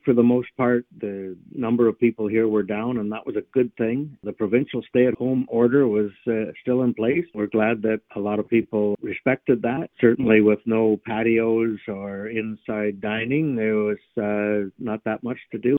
That was Lambton Shores Mayor Bill Weber.